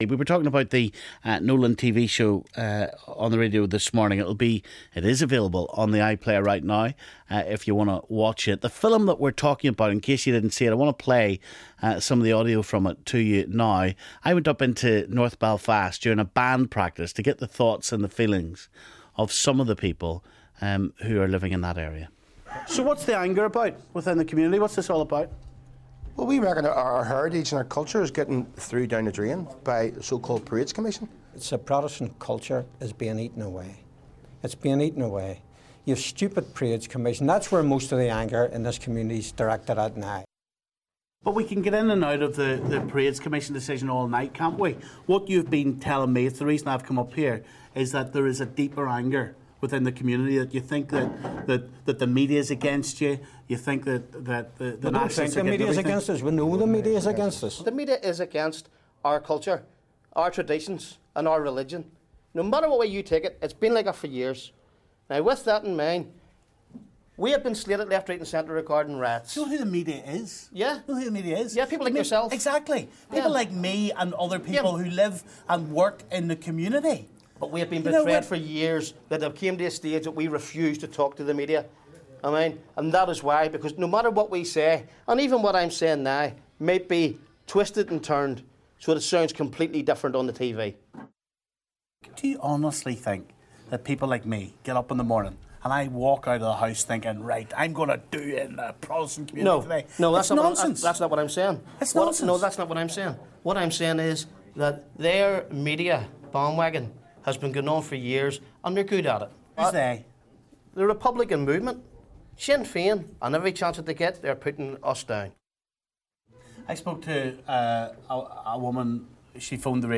Baroness May Blood & Mike Nesbitt discuss the video Stephen made with Loyalists in North Belfast